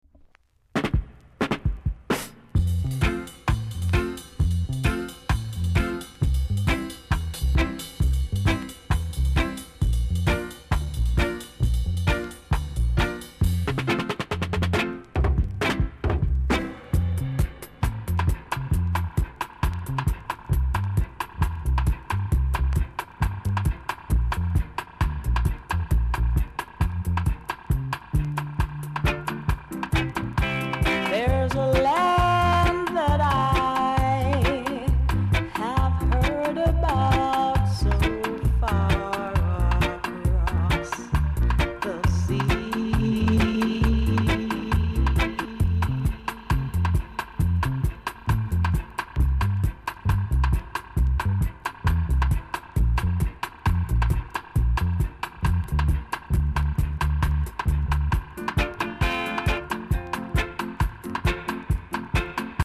※少しVOCALが濁って聴こえます。ほか小さなチリノイズが少しあります。盤は細かい薄い擦り傷が少しあります。